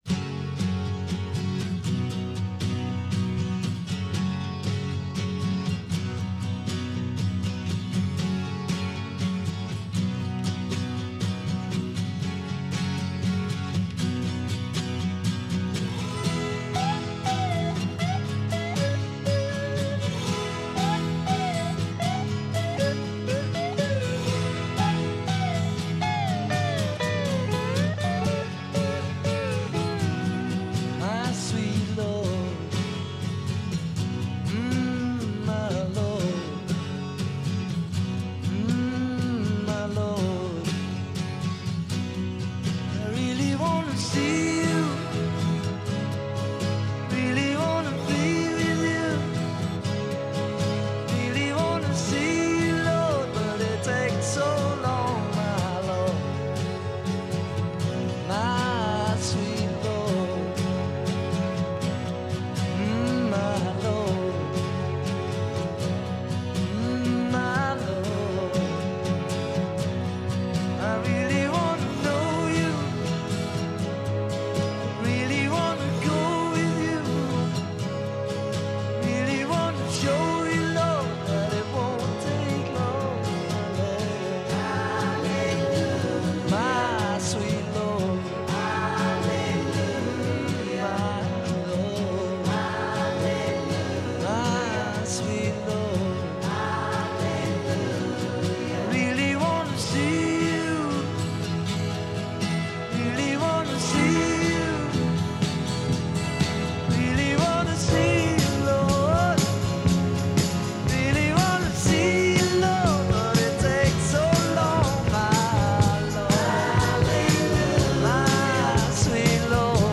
Transferred from a 24/96 high-res download.